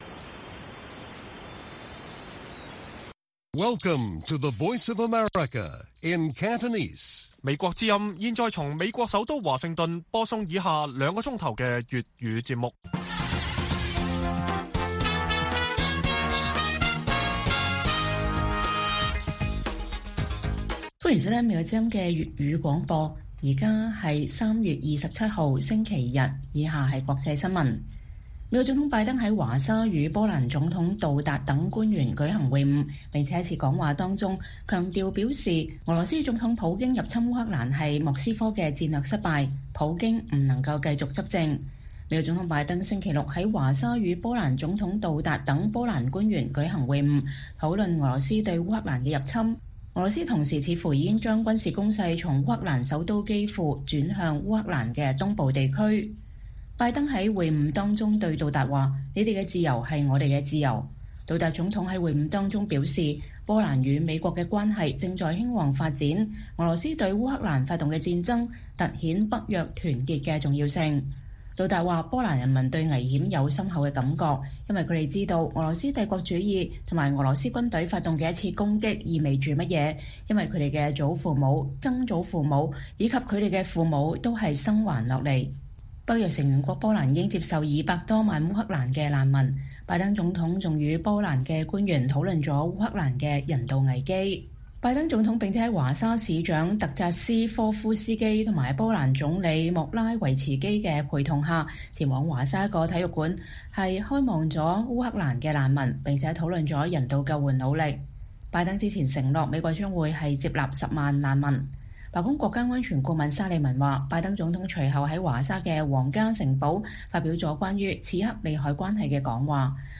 粵語新聞 晚上9-10點：烏克蘭西部城市利沃夫遭受火箭襲擊